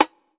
ATR Snare (37).wav